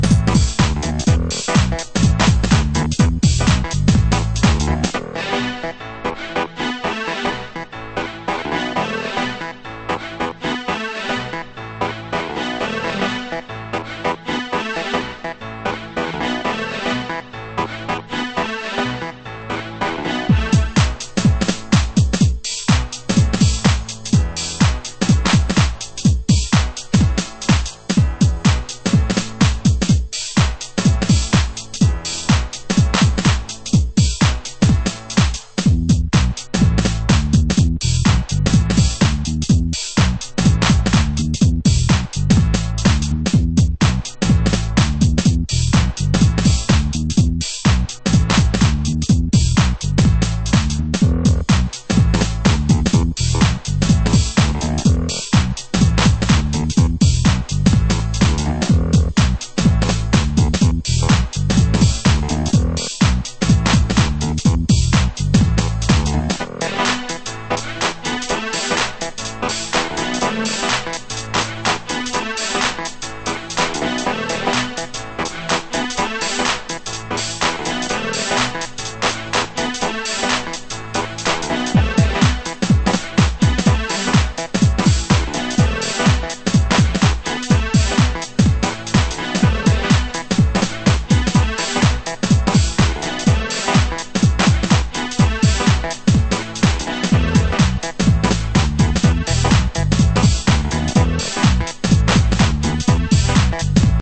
★90'S HOUSE CHICAGO